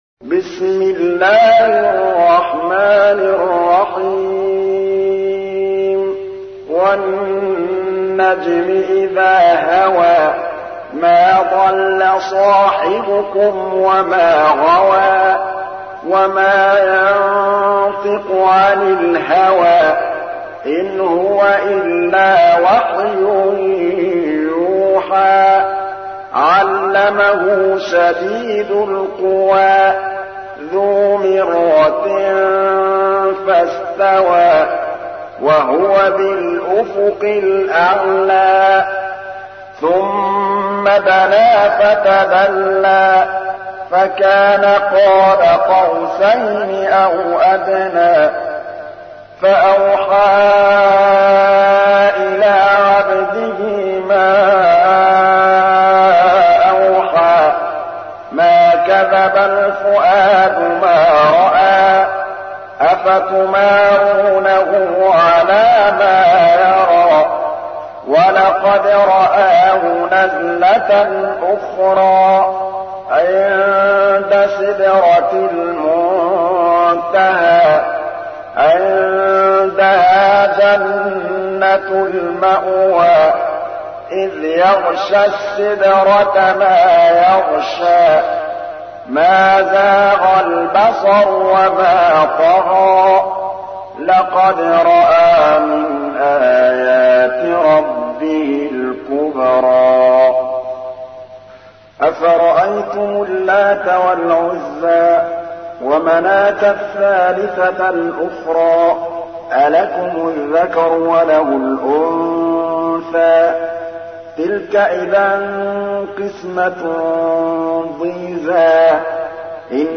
تحميل : 53. سورة النجم / القارئ محمود الطبلاوي / القرآن الكريم / موقع يا حسين